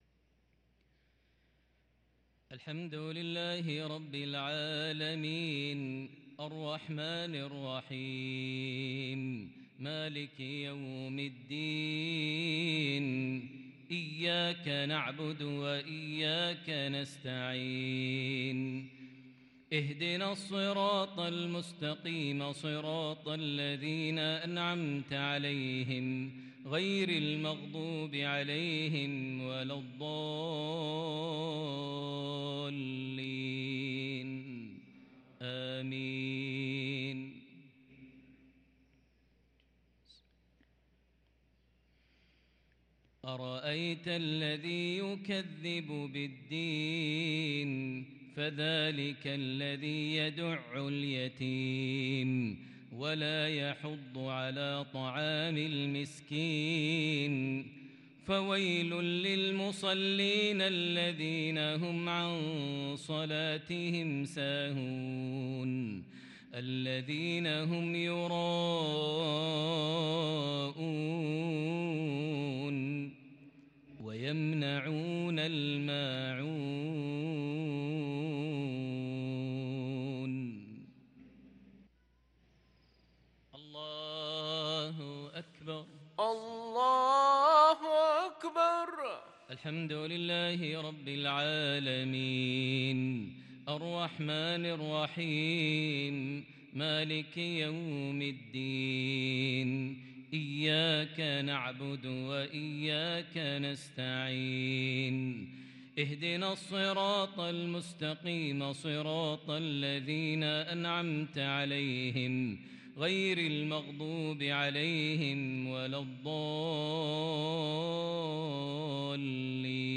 صلاة المغرب للقارئ ماهر المعيقلي 24 جمادي الأول 1444 هـ
تِلَاوَات الْحَرَمَيْن .